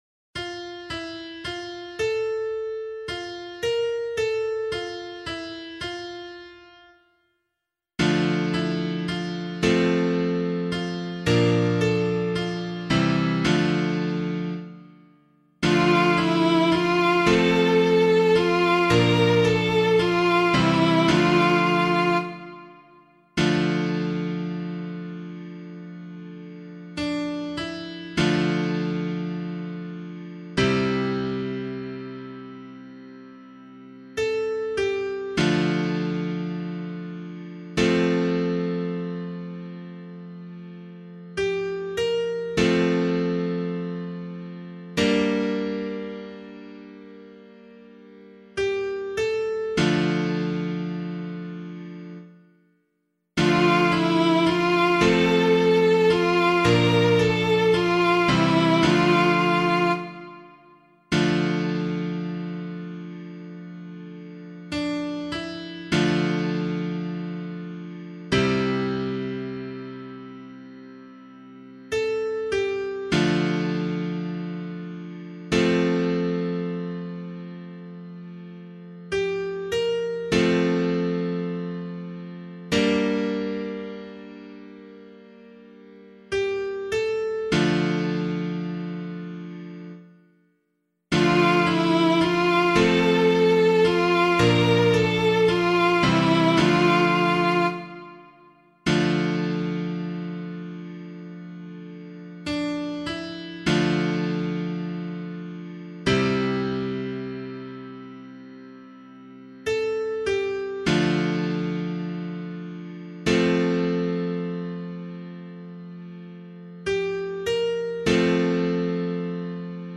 013 Lent 1 Psalm C [LiturgyShare 1 - Oz] - piano.mp3